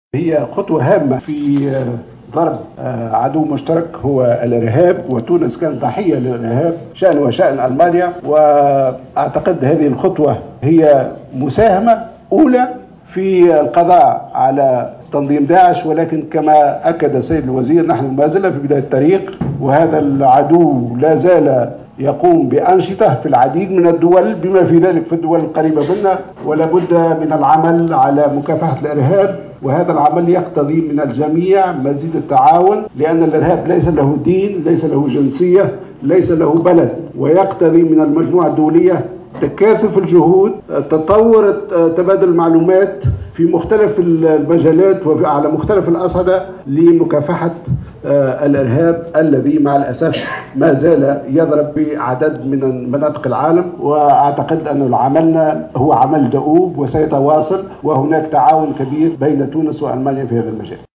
قال وزير الخارجية التونسية "خميس الجيهناوي" خلال ندوة صحفية عقب لقائة اليوم الاثنين، وزير الخارجية الألماني، إنّ القضاء على زعيم تنظيم داعش الإرهابي "أبو بكر البغدادي" خطوة هامة نحو ضرب عدّو مشترك هو الإرهاب .